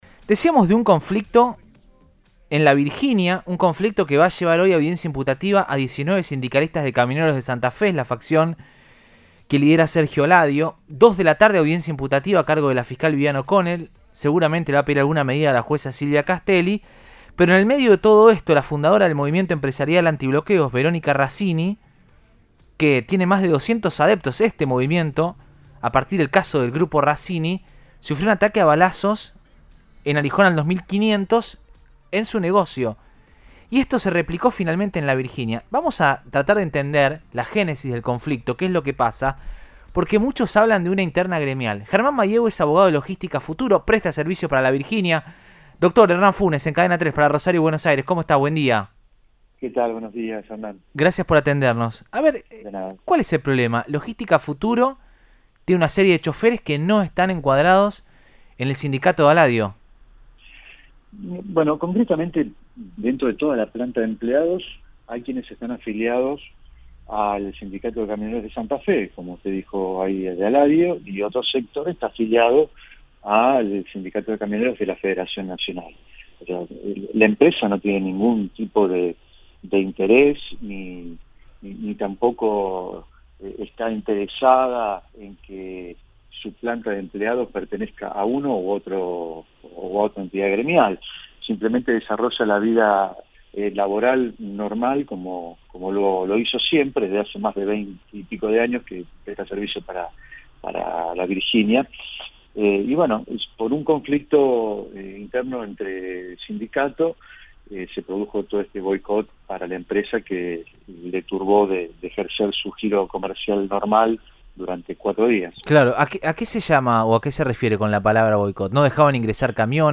contó en Radioinforme 3, por Cadena 3 Rosario, los detalles del conflicto sindical que antecedió las detenciones y la balacera.